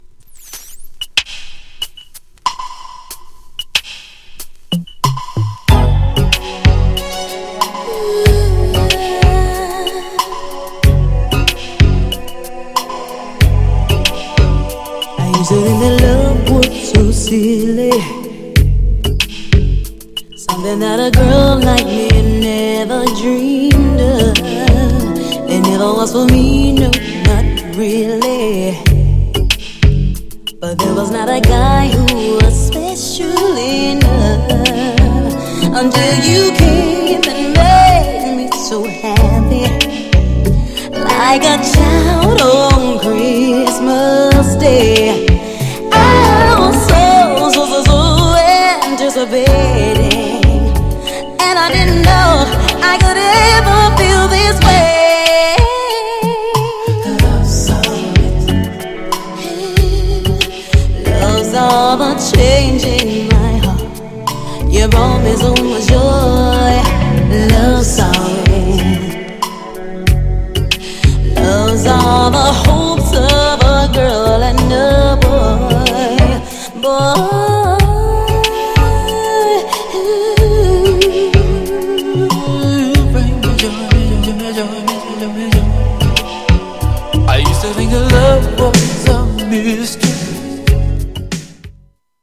GENRE R&B
BPM 86〜90BPM
女性VOCAL_R&B